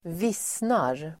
Uttal: [²v'is:nar]